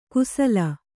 ♪ kusala